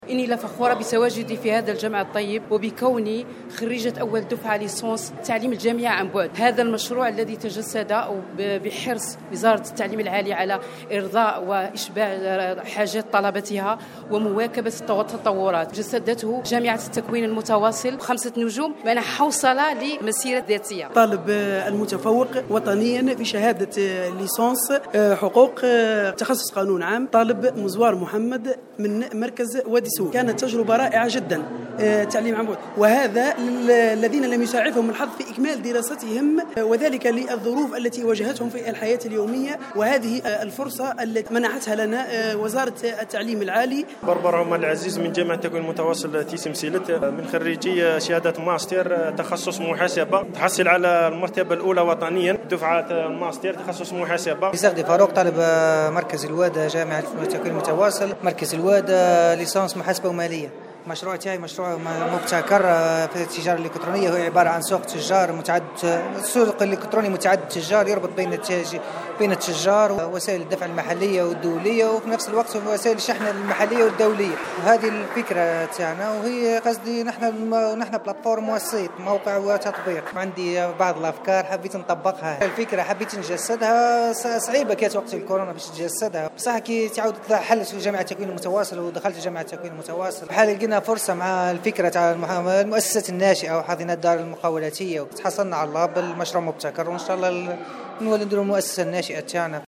نظمت جامعة التكوين المتواصل حفلا ختاميا بمناسبة نهاية الموسم الجامعي 2023-2024 والذي تزامن و عيدي الإستقلال و الشباب و كذا تخرج أول دفعة ليسانس أكاديمي عن بعد في مختلف التخصصات.
الطلبة-المتوجيين-في-حفل-التخرج.mp3